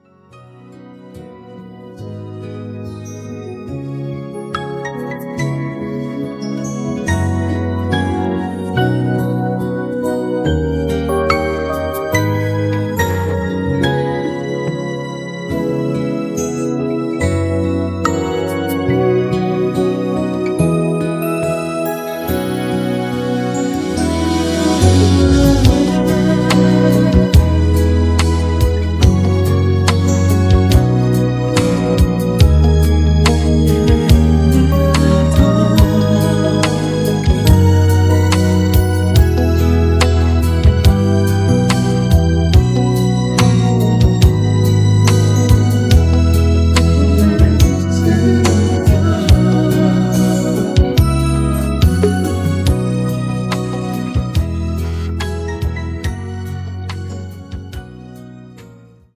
음정 -1키 4:35
장르 가요 구분 Voice MR
보이스 MR은 가이드 보컬이 포함되어 있어 유용합니다.